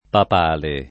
papale [ pap # le ]